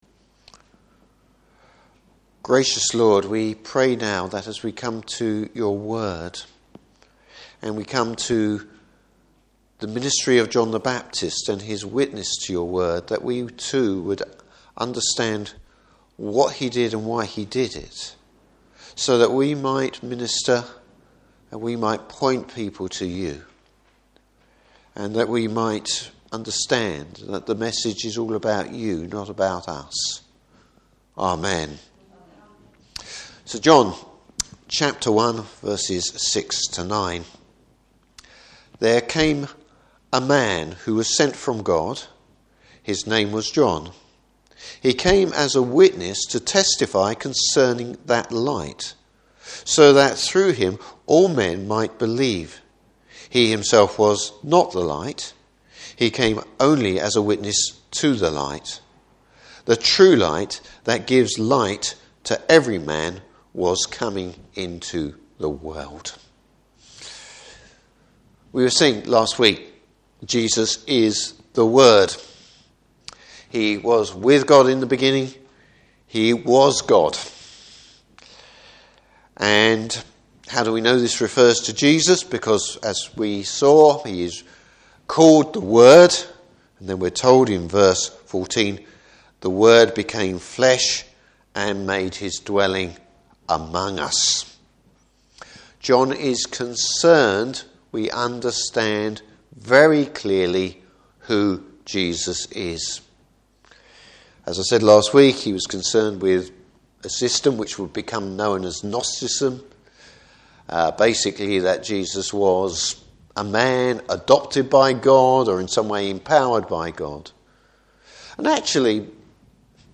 Service Type: Morning Service What was John the Baptist’s role?